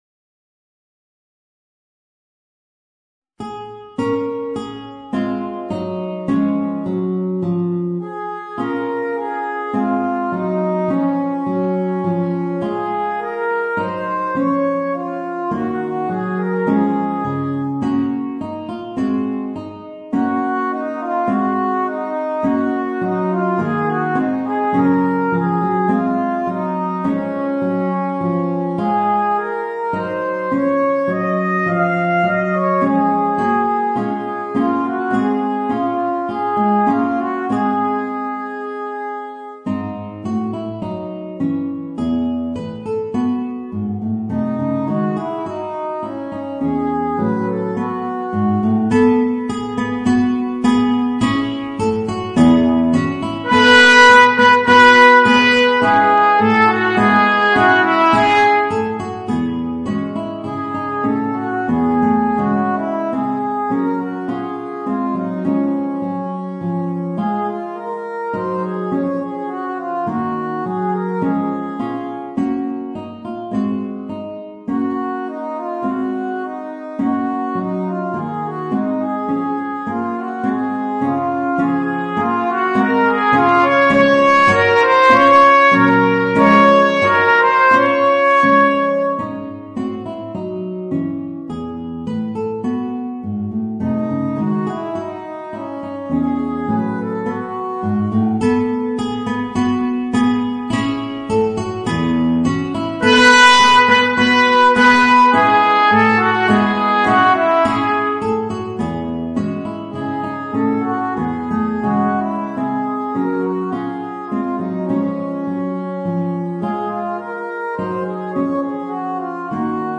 Voicing: Trumpet and Guitar